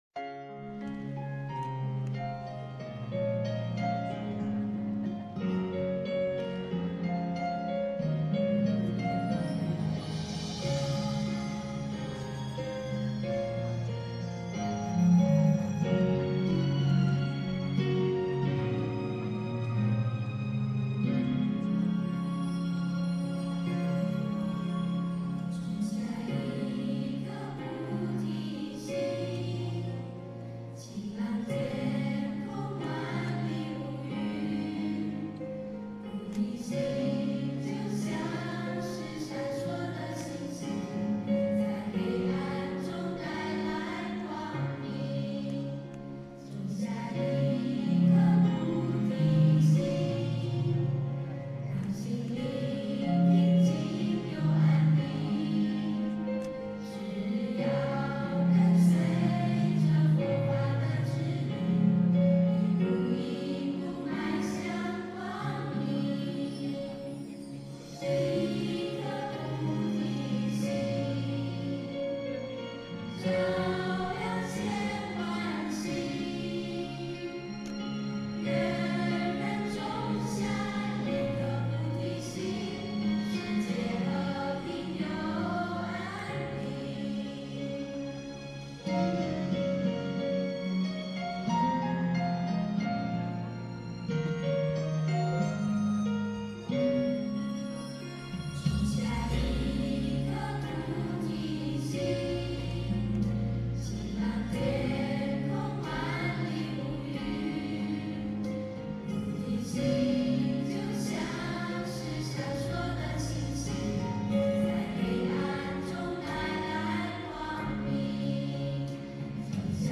音频：新加坡佛友合唱《菩提心》一曲一天堂 一素一菩提！